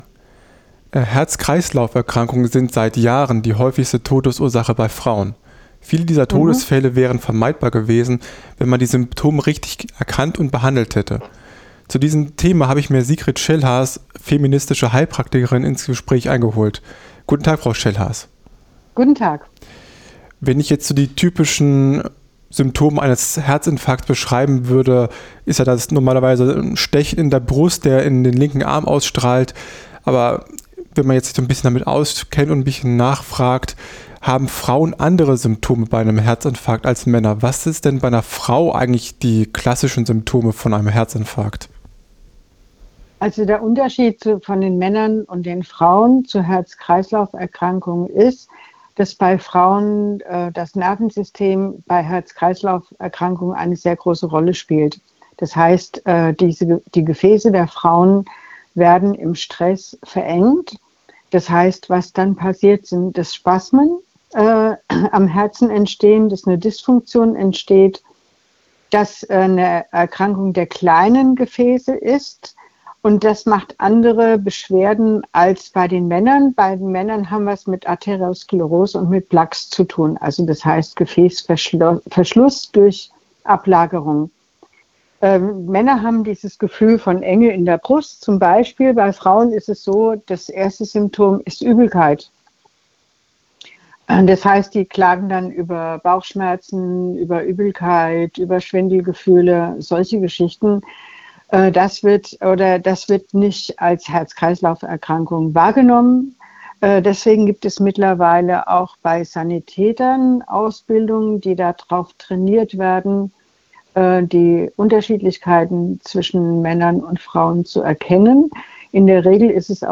Ein inklusiver Blick hilft allen - Ein Gespräch